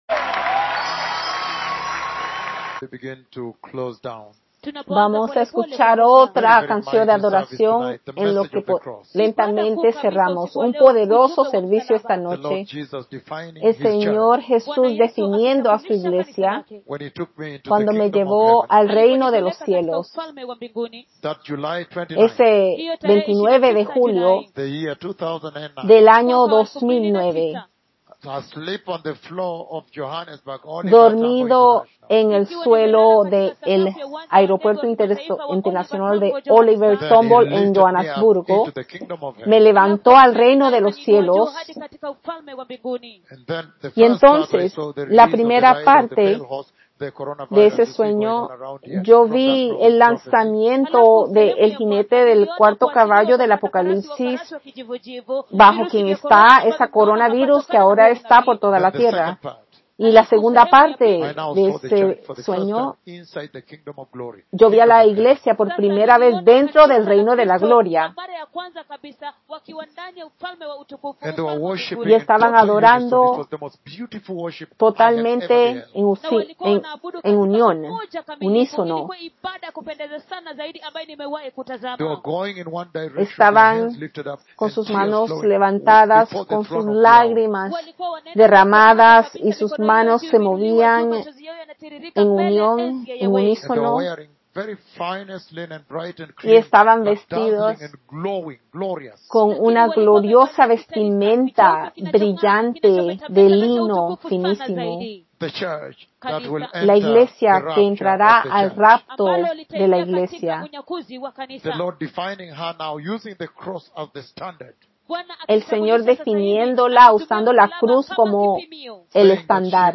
SERVICIO DE MEDIA SEMANA MIERCOLES 31 MARZO 2021-Audio-2-5Download